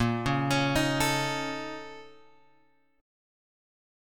A#mM11 chord {6 4 x 6 4 5} chord